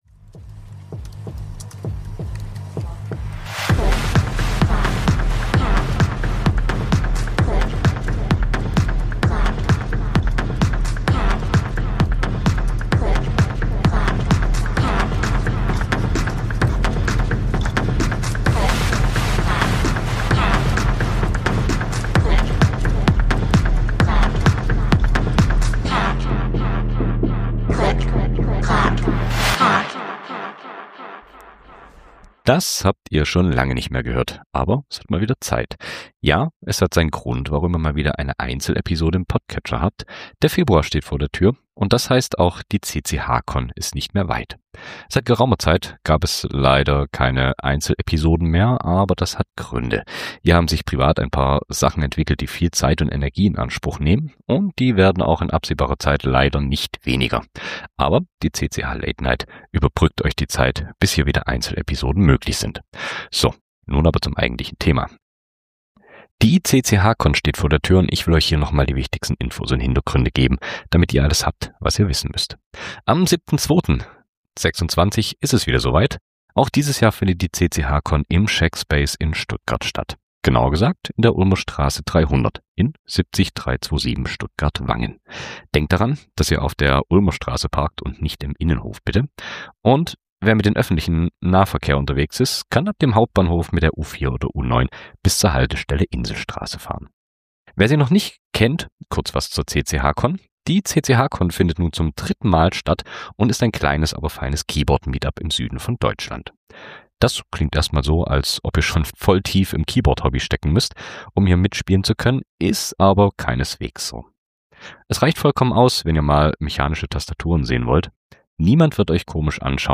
Intro / Outro: Jazz Background Loop